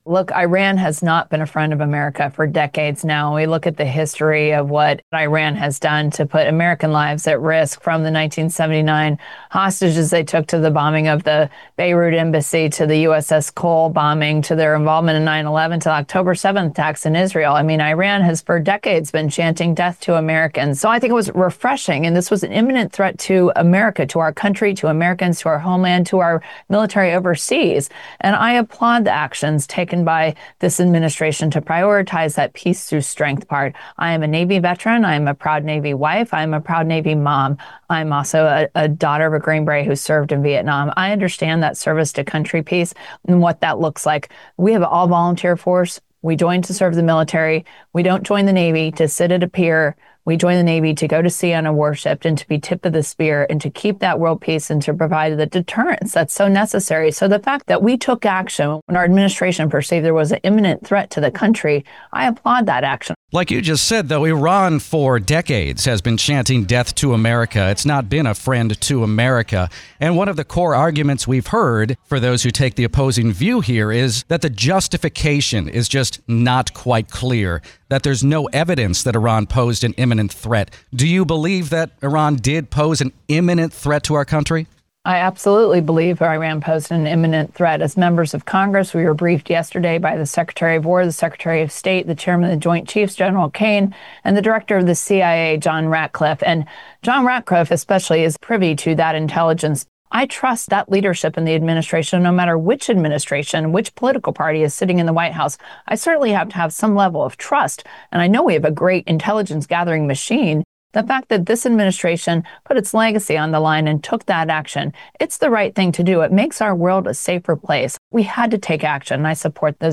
Virginia Republican Congresswoman Jen Kiggans joined WTOP to discuss her support for the Trump administration's actions in Iran.